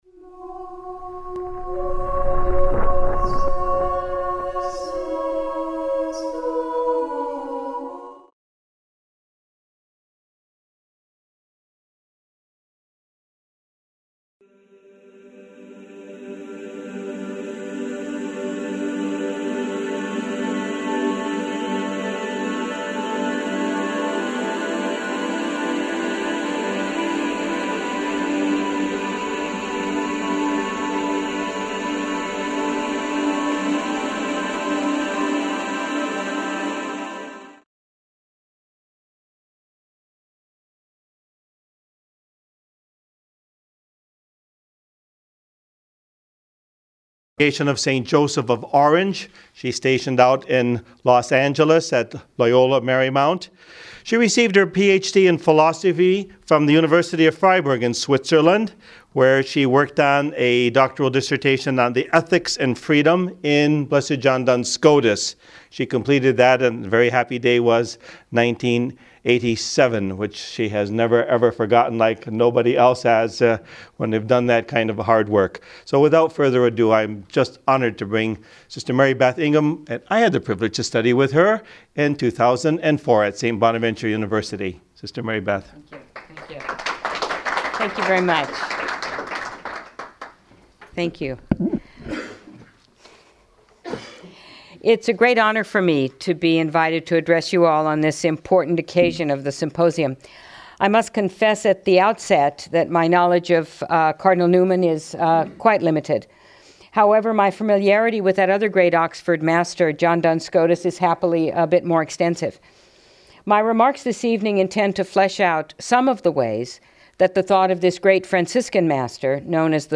The keynote address for Newman-Scotus Symposium